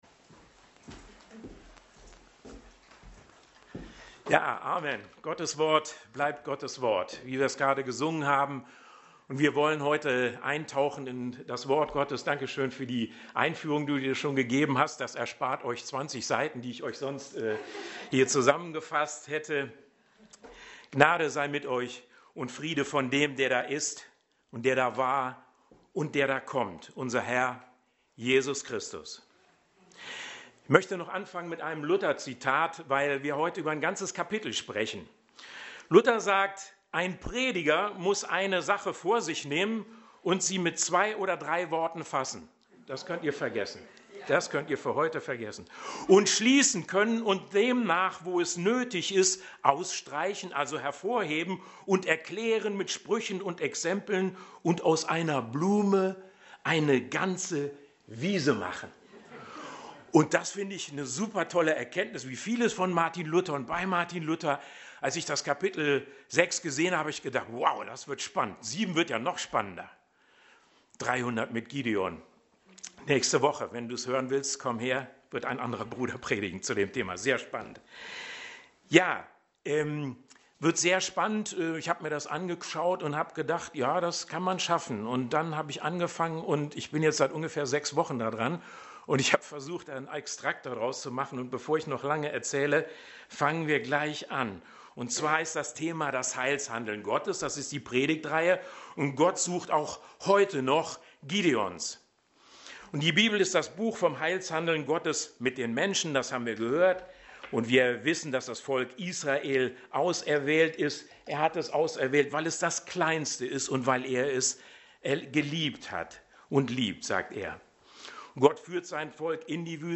Sonntagspredigt in der EfG Bonn